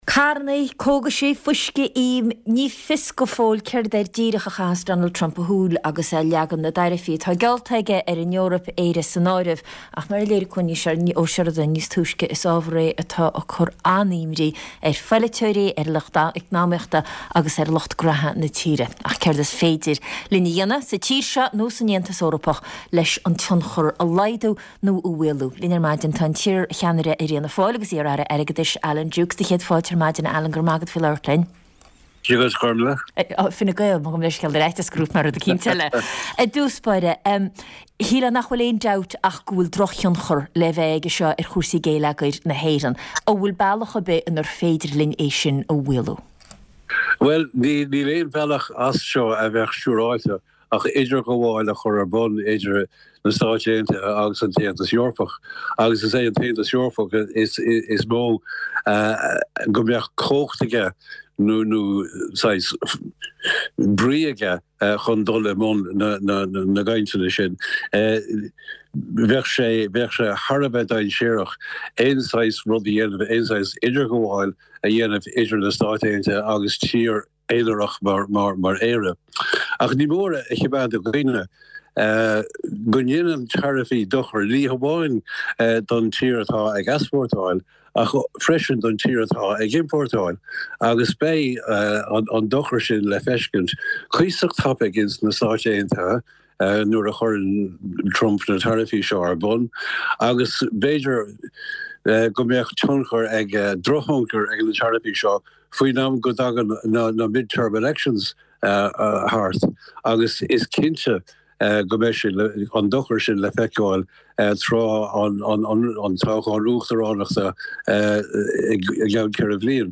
tráchtaire, polaitíocht na Breataine.